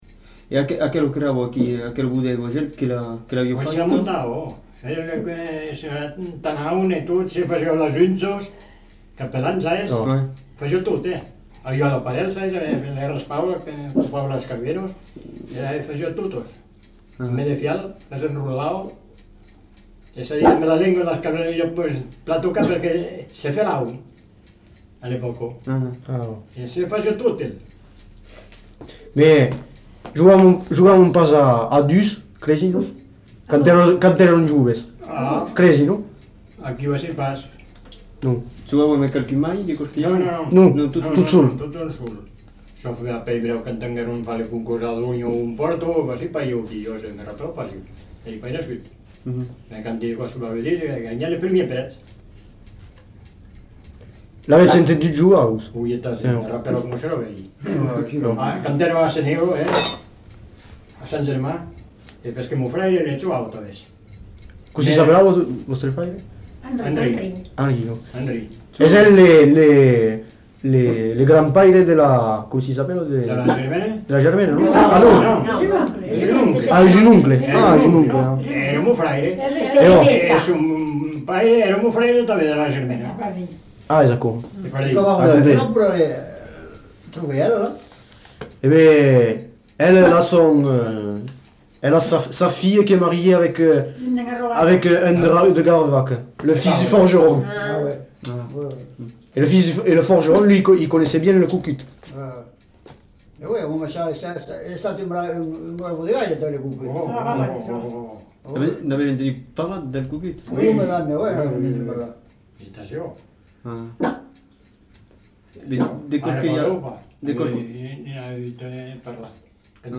Lieu : La Pomarède
Genre : témoignage thématique